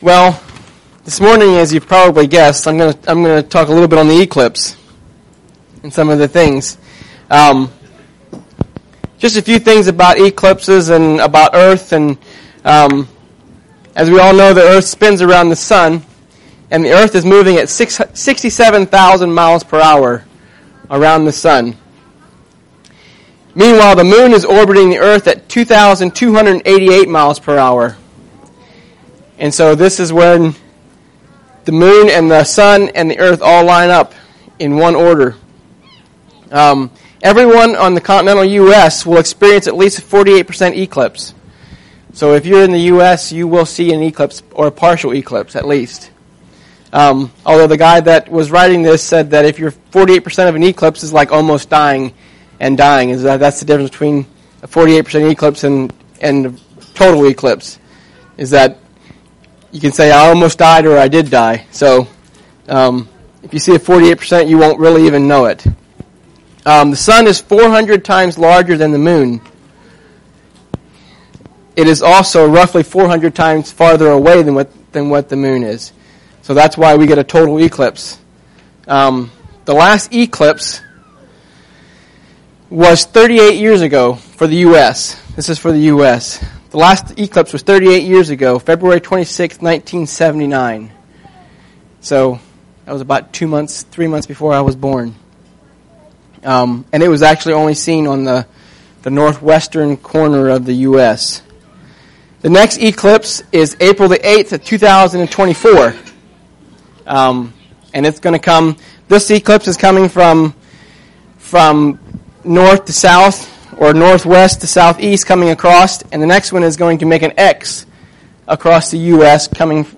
Sorry for the audio quality. There was a glitch in the recording software.